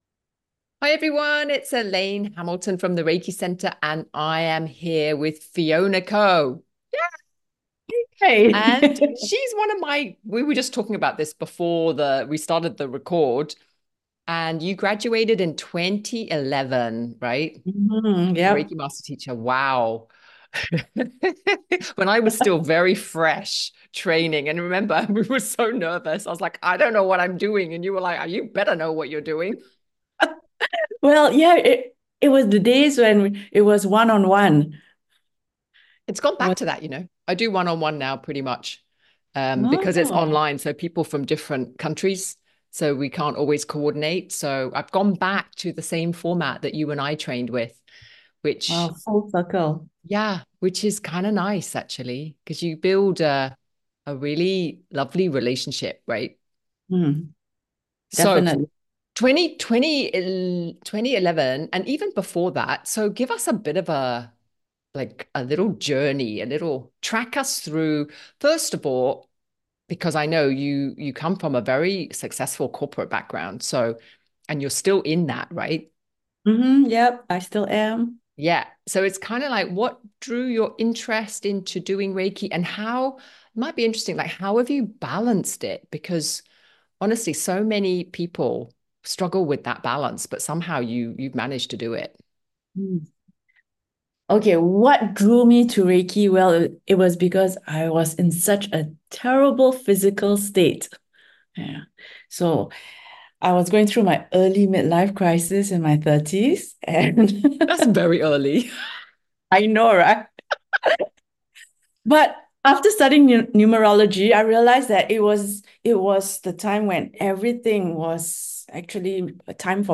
In this lovely conversation we meander through a life with Reiki, the different stages that unfold for people and how Reiki provides the perfect foundation for building a meaningful and fulfilling life.